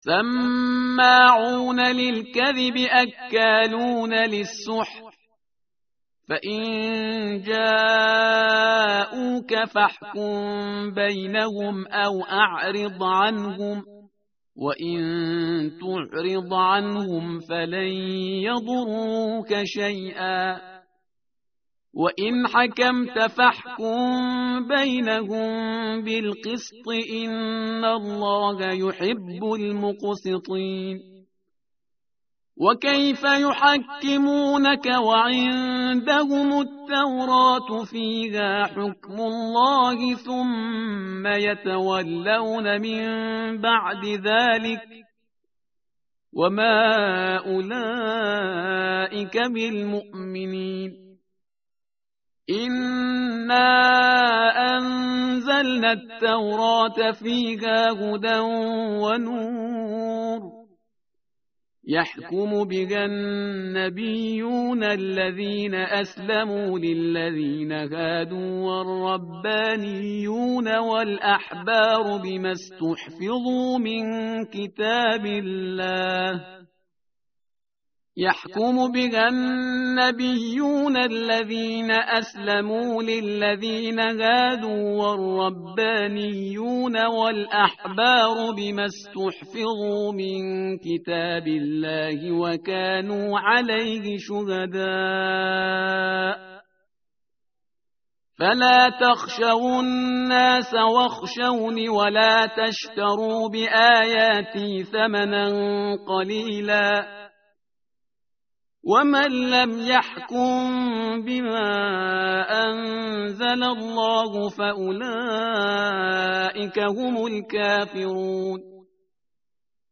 متن قرآن همراه باتلاوت قرآن و ترجمه
tartil_parhizgar_page_115.mp3